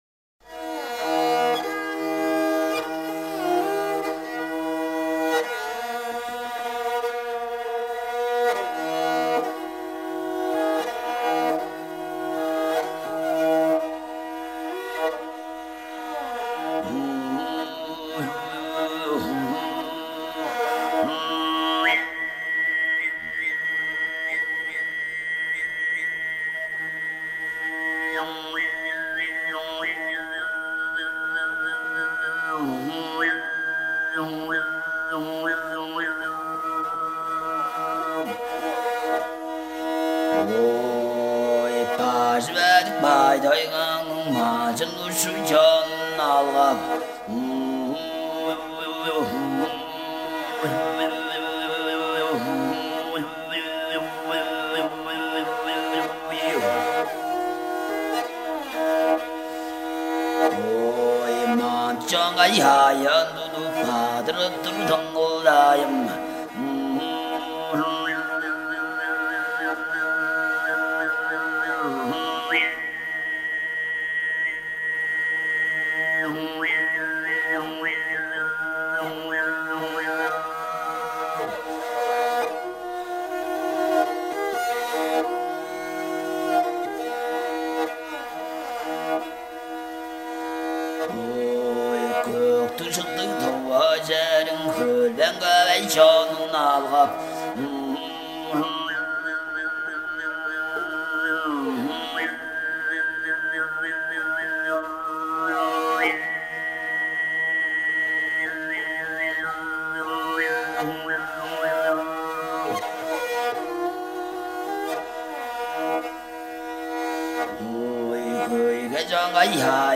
Esempi di canto di gola (per i file audio cliccare con tasto destro e "salva con nome")
Sygyt, Kargyraa